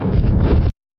スマッシュ
平手打ち うなり声